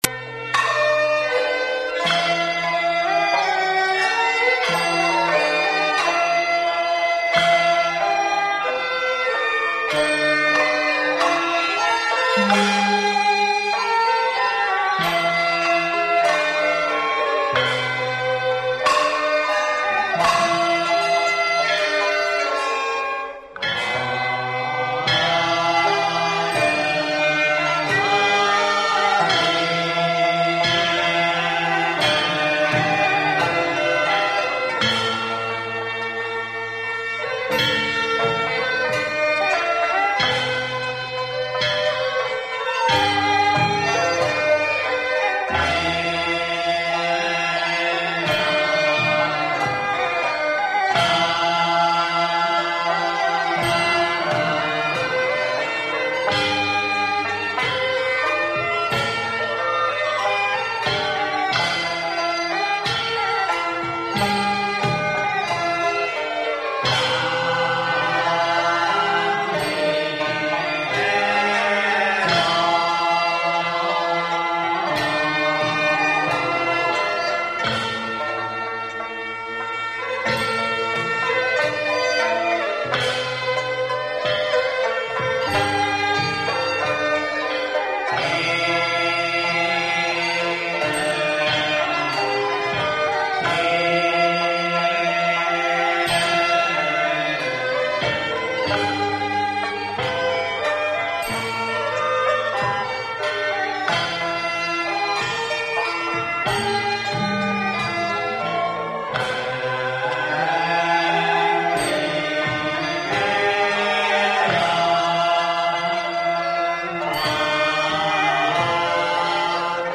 上海道教音乐是一个各乐种成分相互融汇、并具有江南独特音乐风格和丰富道教色彩的音乐。它伴随着上海道教浩繁的斋醮科仪的进行，灵活巧妙地在各种场合穿插运用，有时鼓声震天，气势磅礴，以示召神遣将、镇邪驱魔；有时丝竹雅奏，余音绕梁，使人身心清静，如入缥缈之境。